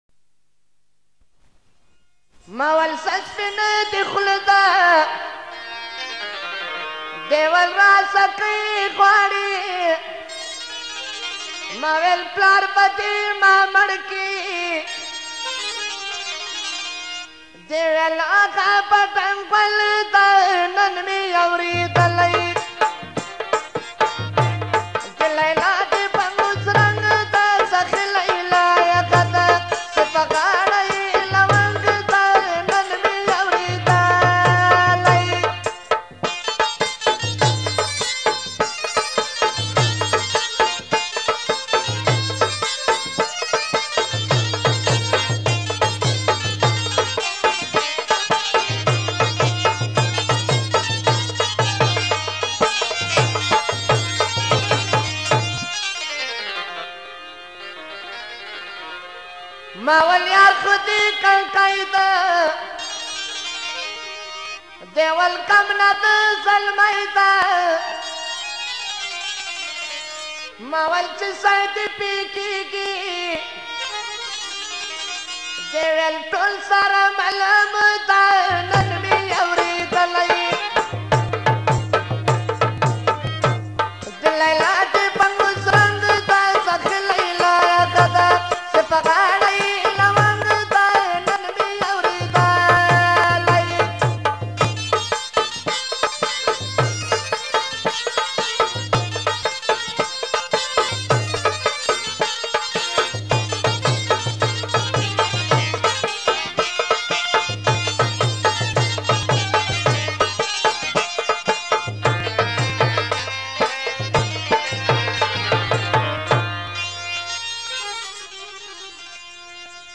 Attan